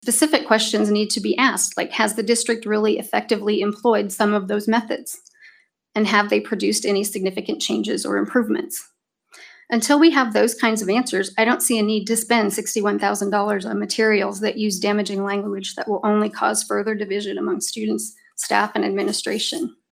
A packed crowd during Wednesday’s USD 383 meeting for public comment in regards to culturally responsive teaching and learning training.